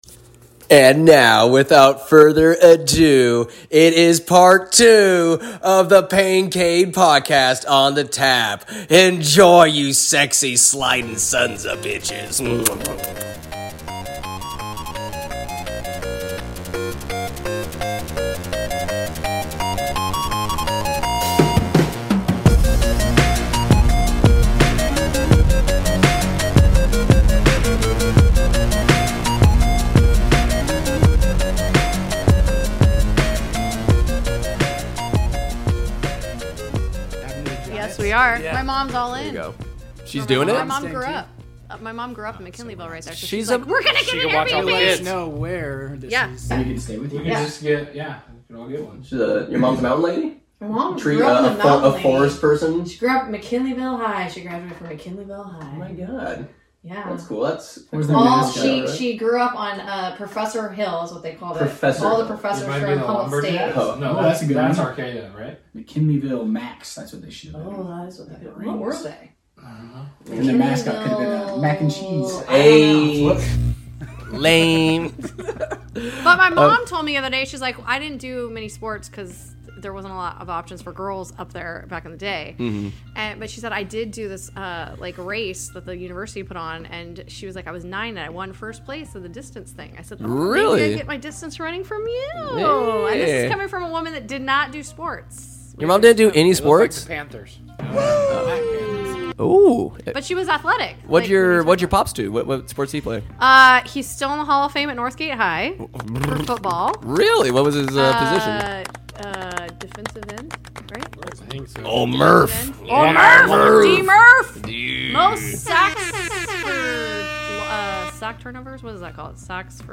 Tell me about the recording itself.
Sketchy audio and all! Enjoy the banter and laughs you sexy sliders!